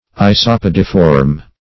Search Result for " isopodiform" : The Collaborative International Dictionary of English v.0.48: Isopodiform \I`so*pod"i*form\, a. [Isopod + -form.]
isopodiform.mp3